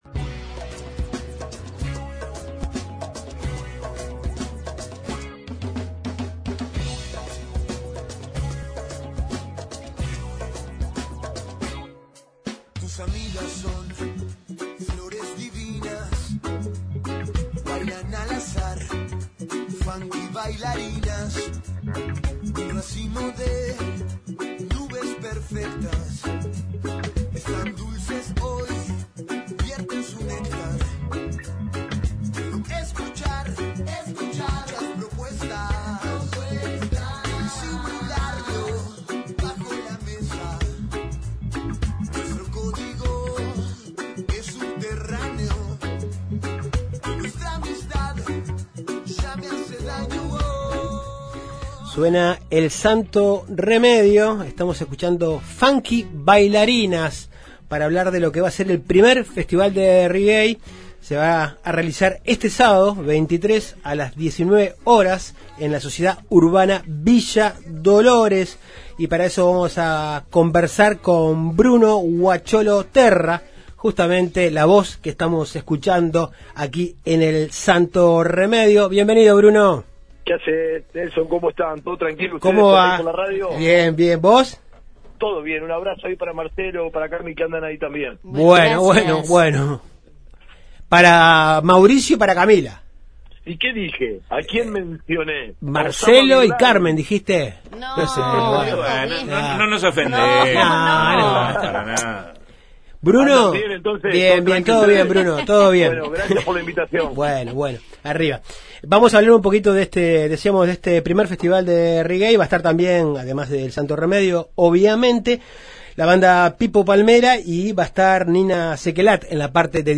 Justos y pecadores conversamos con